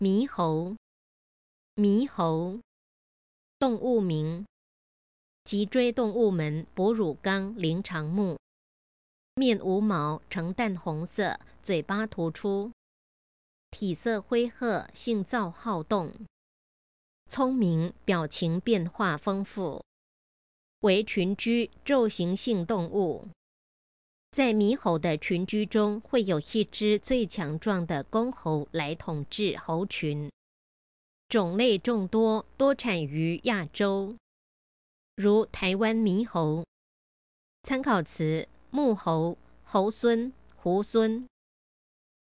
Advanced Information 【獼】 犬 -17-20 Word 獼猴 Pronunciation ㄇㄧ ˊ ㄏㄡ ˊ ▶ Definition 動物名。